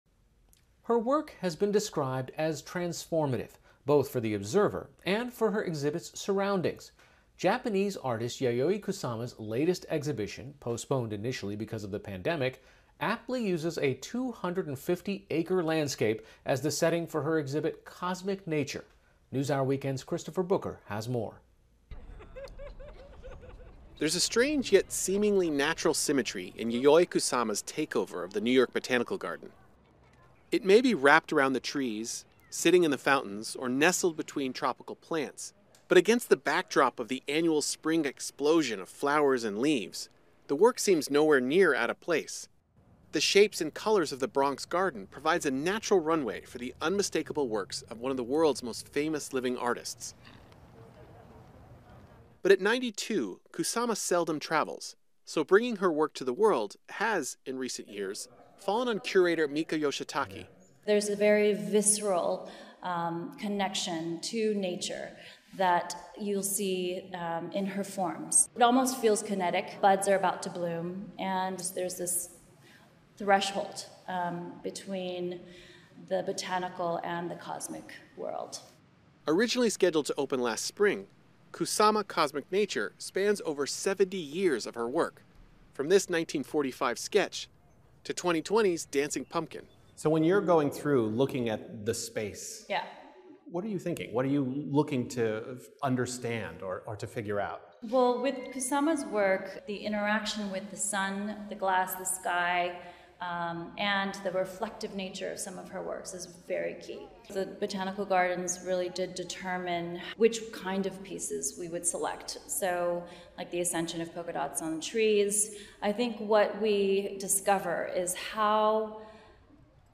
英语访谈节目:一场艺术与自然界的对话